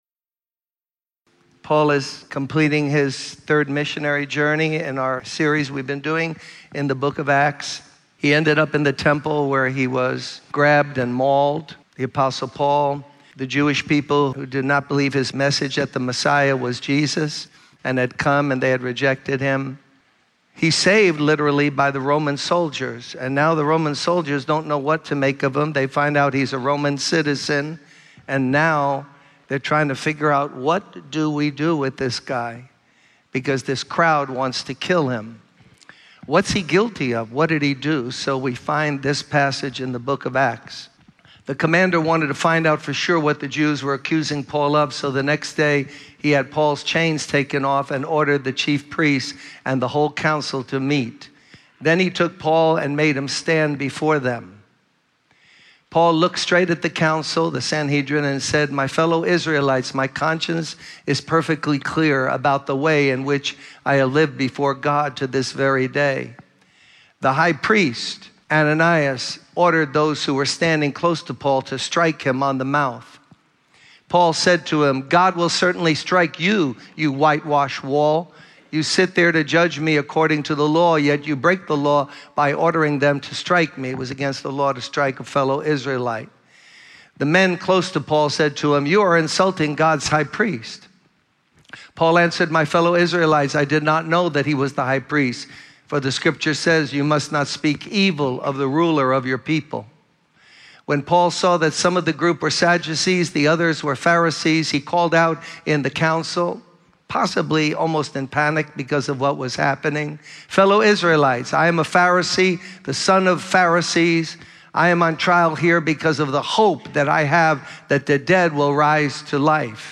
In this sermon, the speaker emphasizes the importance of bearing witness and giving testimony about Jesus.